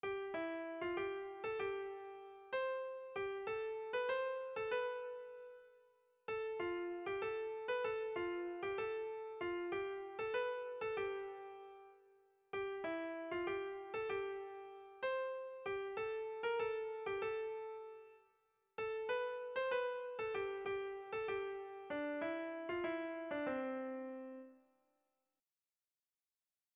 Irrizkoa
Seiko txikia (hg) / Hiru puntuko txikia (ip)
A1BA2D